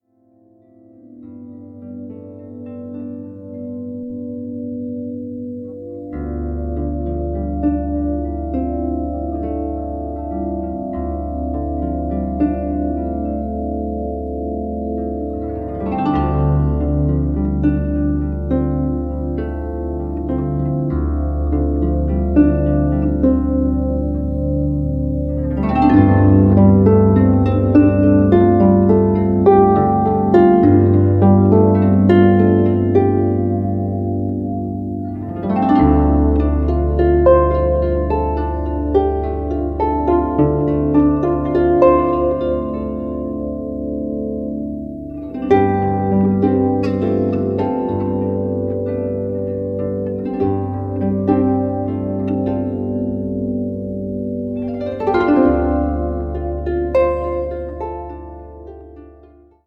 A Music meditation - from the forest to the sea
Featuring harp, wind instruments,
sounds of the forest and ocean